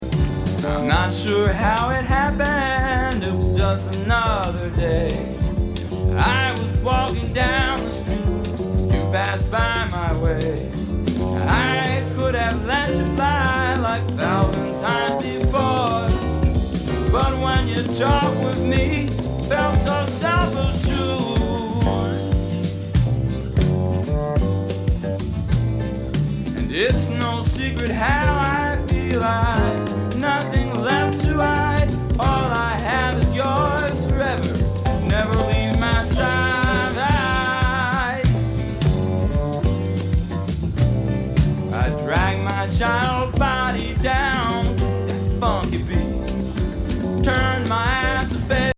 リリース!極上メロのSSWサウンドながら、どこか屈折したアレンジが鮮烈。